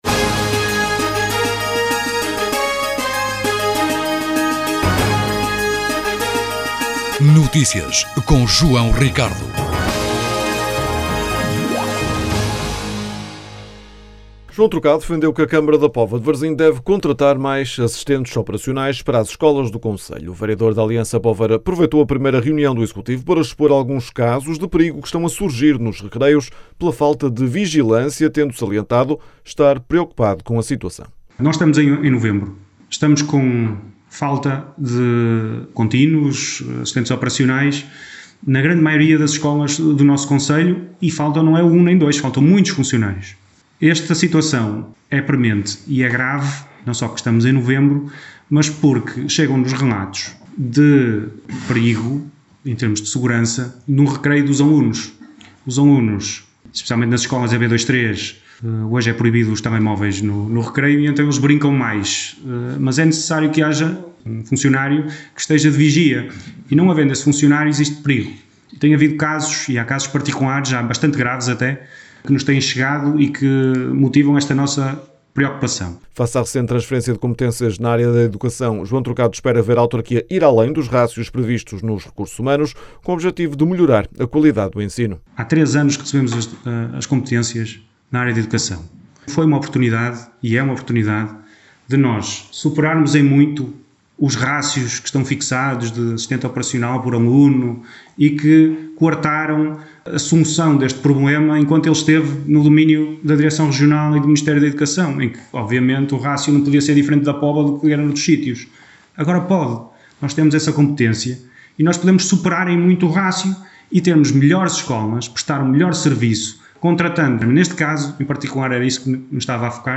O vereador da Aliança Poveira aproveitou a primeira reunião do executivo para expor alguns casos de perigo que estão a surgir nos recreios pela falta de vigilância, tendo salientado estar preocupado com esta situação.